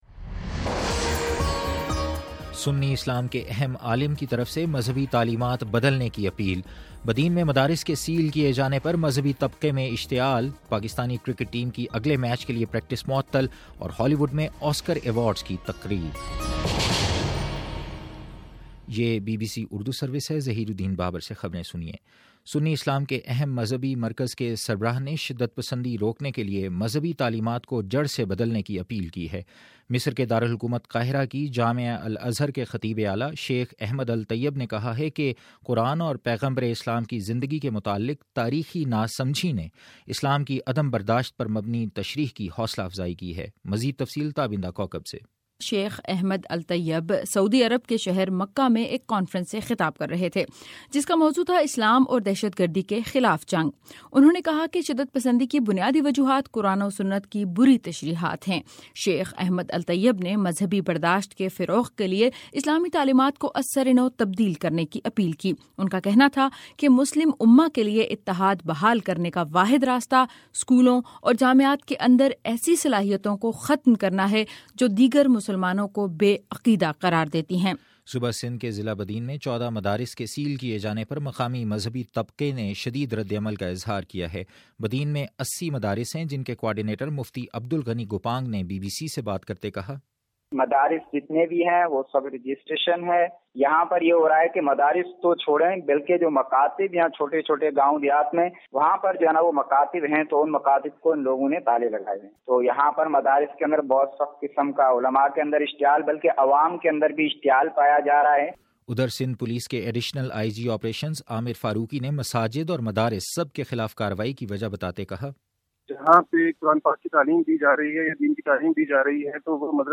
فروری 23 : صبح نو بجے کا نیوز بُلیٹن